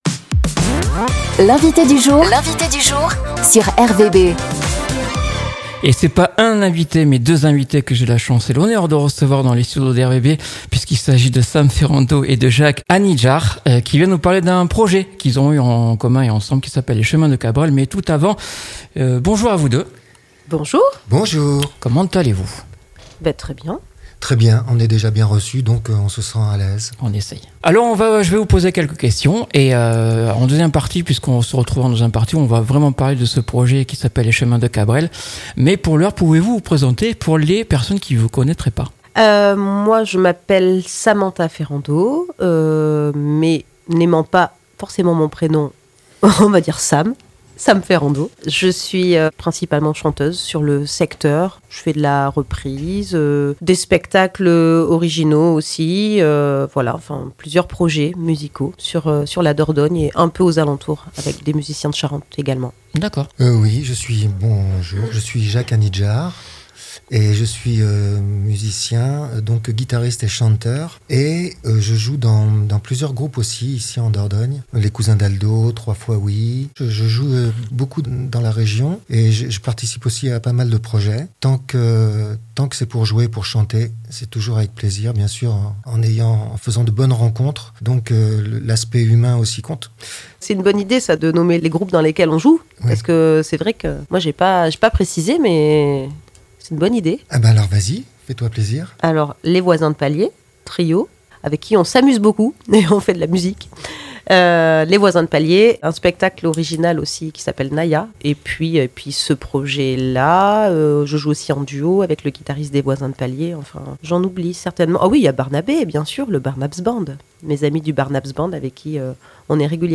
Un voyage au cœur de l'univers Cabrel - Interview